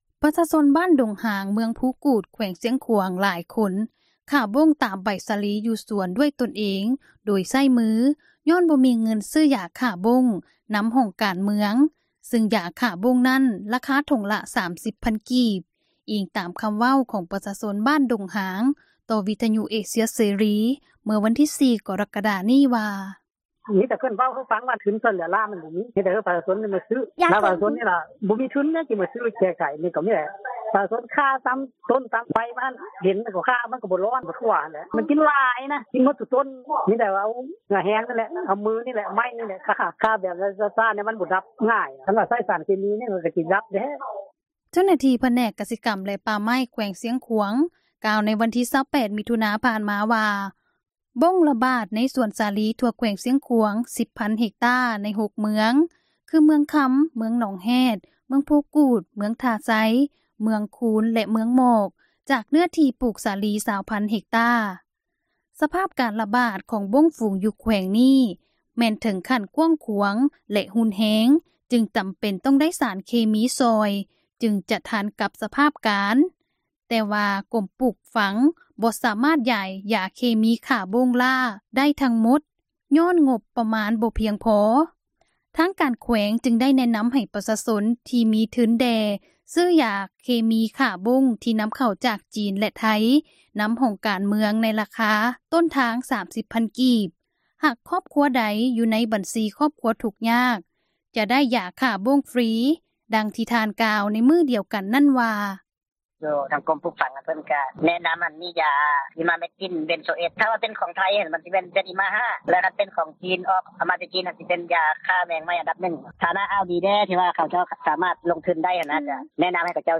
ບ້ານດົງຫາງບາງຄອບຄົວ ບໍ່ມີຢາ ຂ້າບົ້ງ – ຂ່າວລາວ ວິທຍຸເອເຊັຽເສຣີ ພາສາລາວ